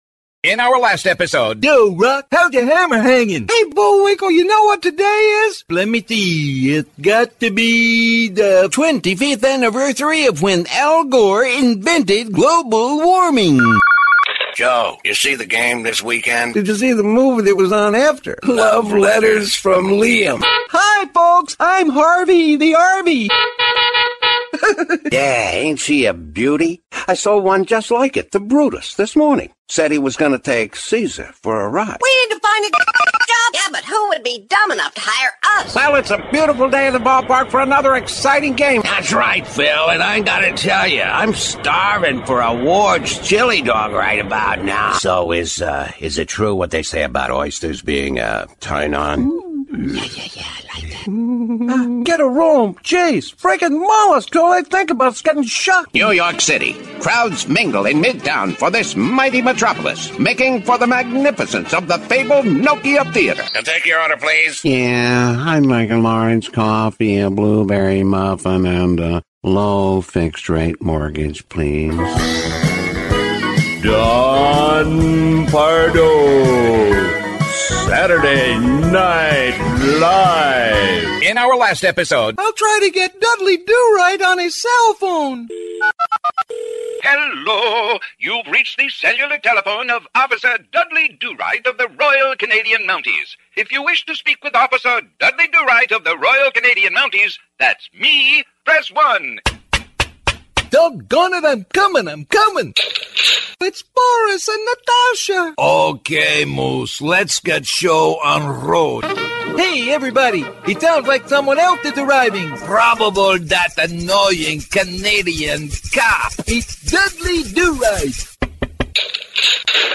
Middle Aged
Quiet in-house audio booth. Classic 1981 Neumann U-87.